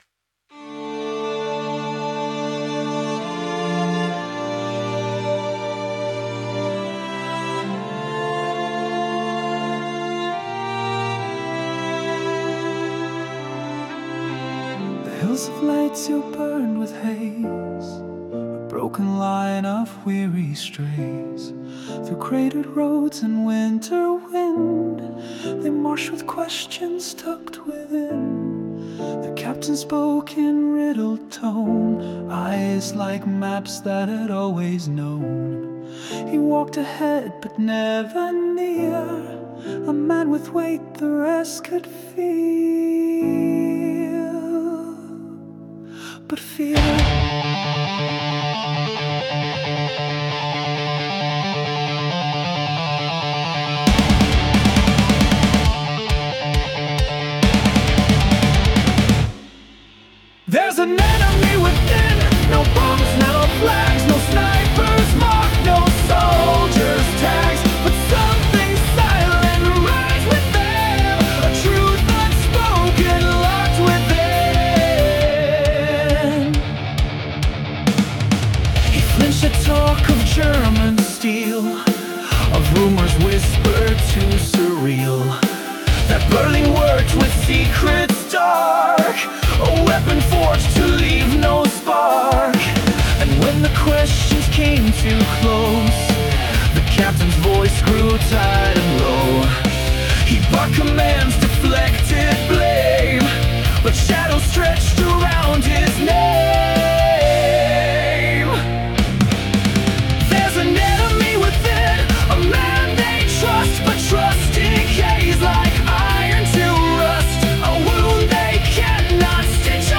power metal concept album